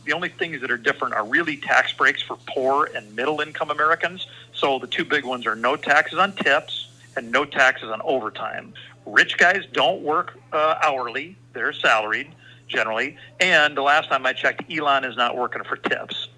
WASHINGTON, D.C.(Northern Plains News)- South Dakota Congressman Dusty Johnson defended the so-called “big, beautiful bill” during a telephone town hall last Wednesday night.